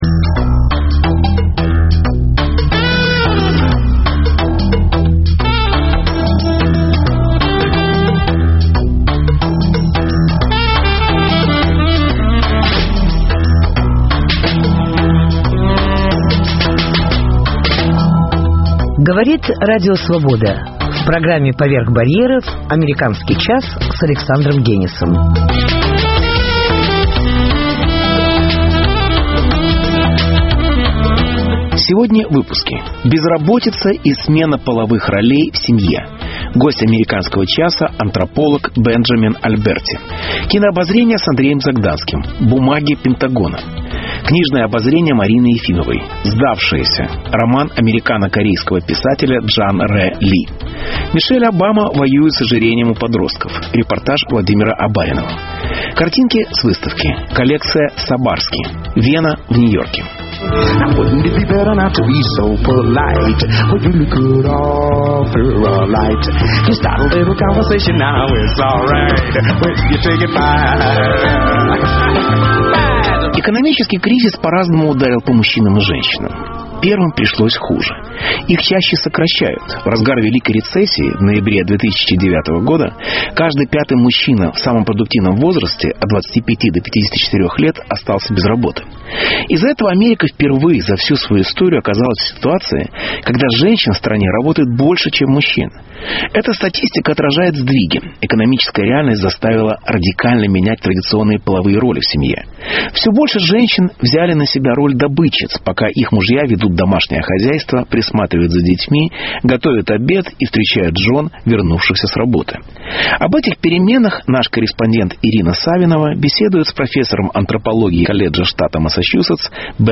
Мишель Обама воюет с ожирением. Репортаж